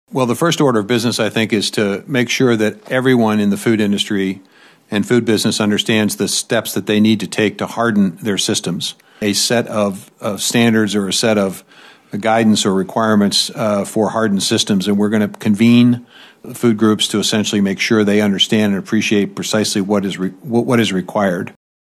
But USDA Secretary Vilsack says agriculture must be ready.